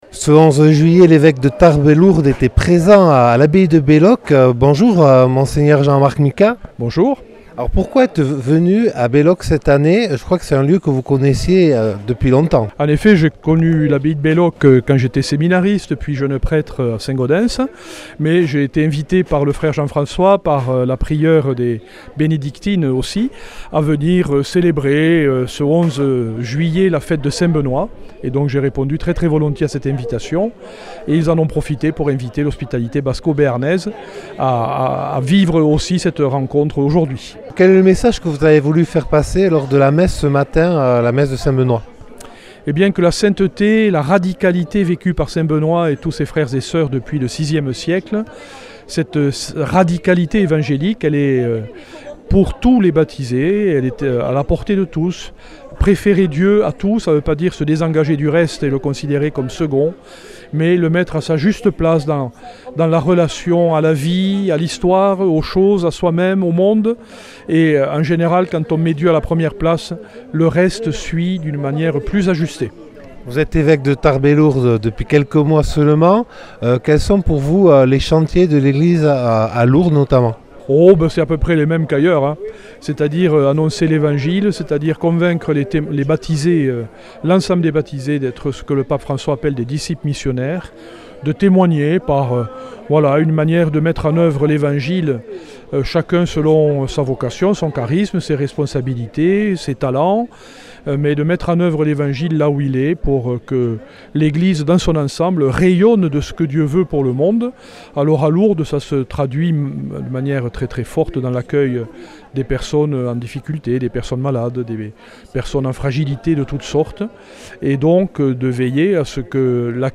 A l’invitation des moniales et des moines, Mgr Jean-Marc Micas a présidé le 11 juillet 2023 la fête de Saint Benoît en présence des membres de l’Hospitalité Basco-Béarnaise Notre-Dame de Lourdes.